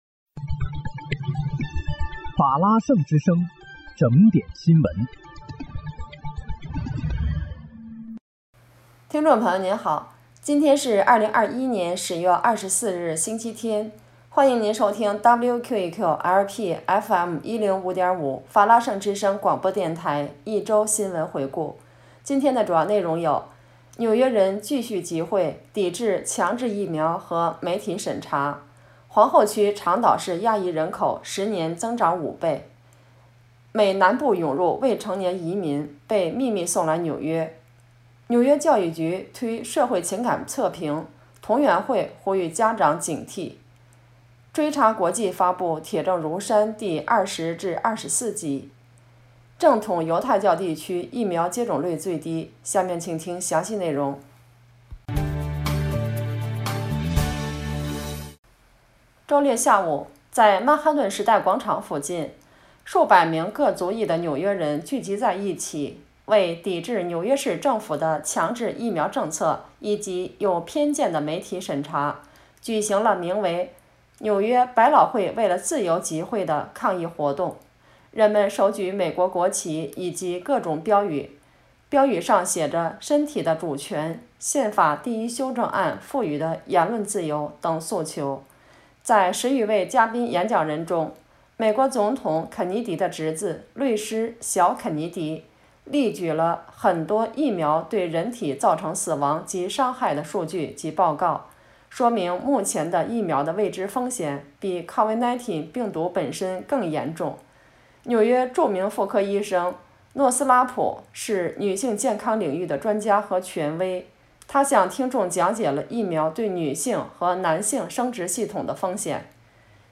10月24日（星期日）纽约整点新闻